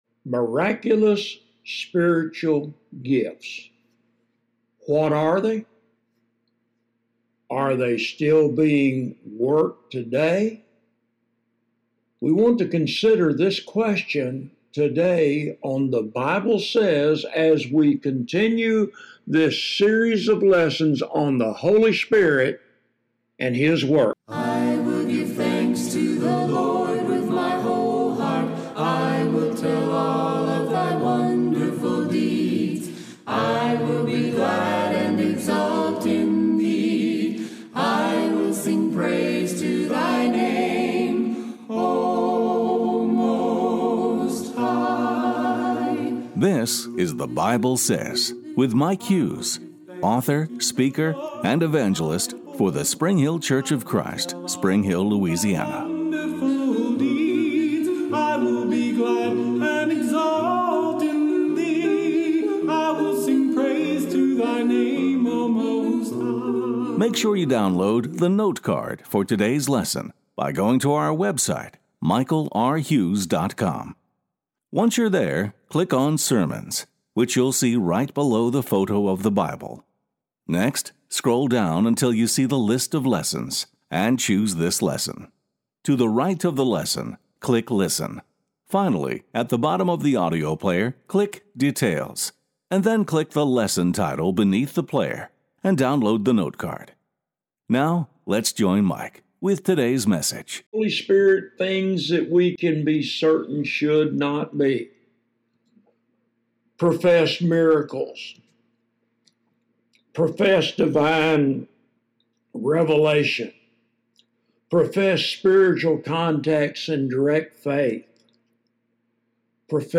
Sermons —